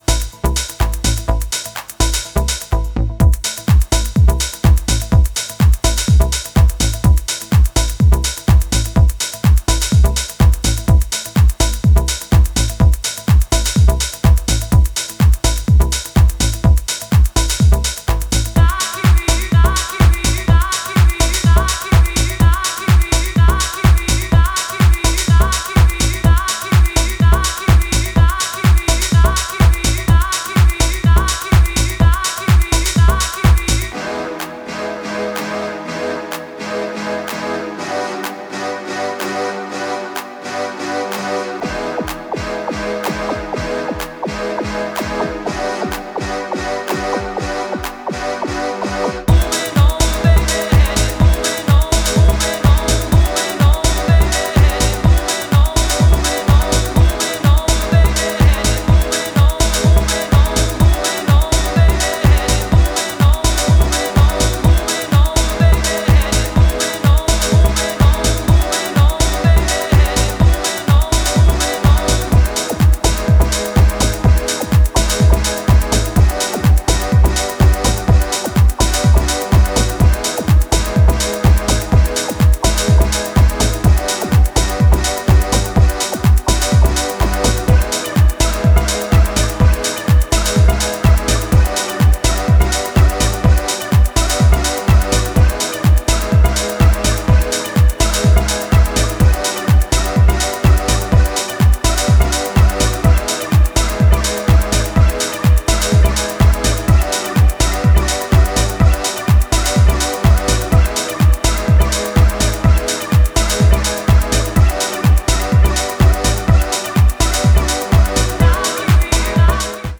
浮遊感のあるシンセリフがリフレインする